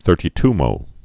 (thûrtē-tmō)